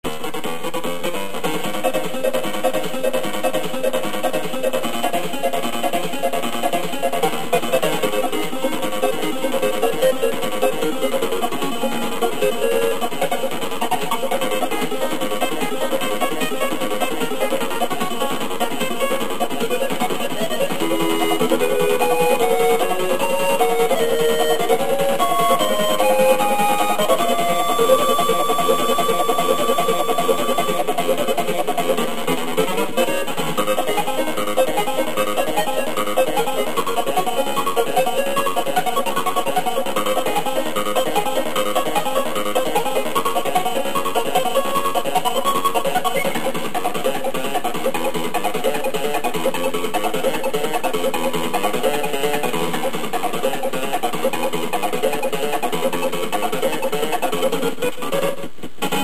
They are now in mono sound at 32khz, 64kbps in .mp3 format.